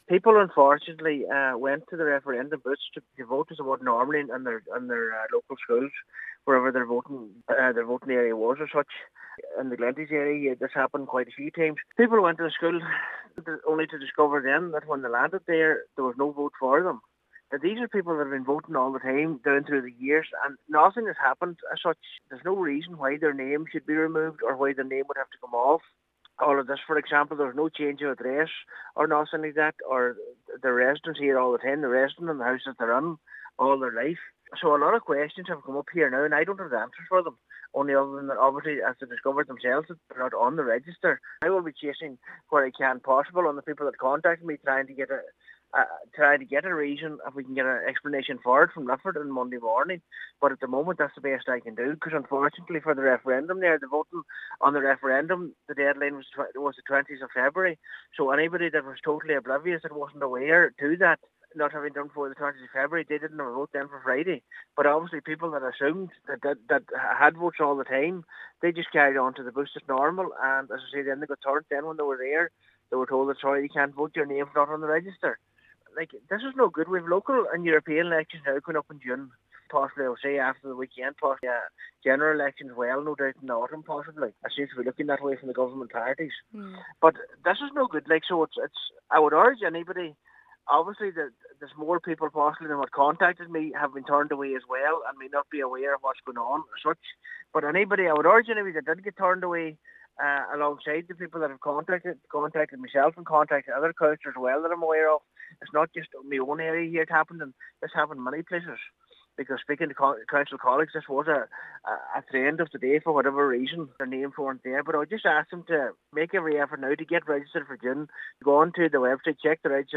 Councillor Michael McClafferty, Cathaoirleach of Glenties Municipal District, is concerned as to why this happened, and urges people to ensure they are registered before the upcoming local and European elections in June: